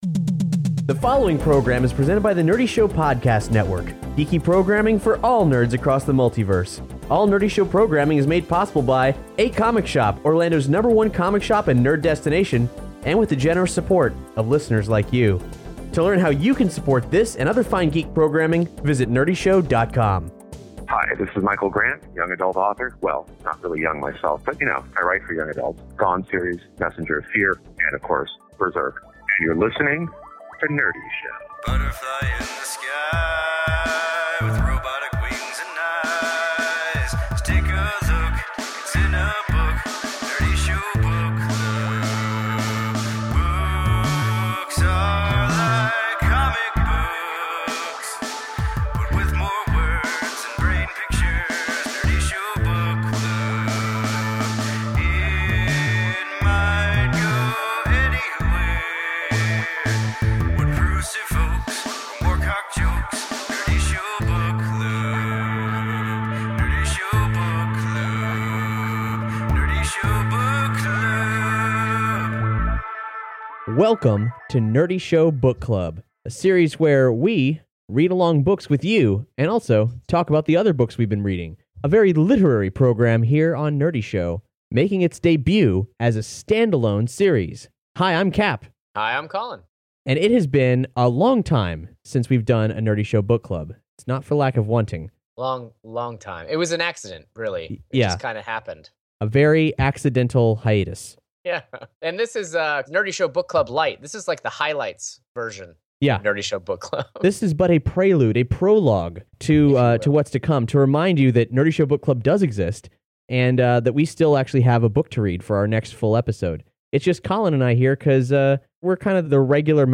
In these literary episodes, we share the books we’re currently reading, do dramatic readings from the best and worst nerd literature, talk with notable geek authors, and discuss a book we’ve read along with the community.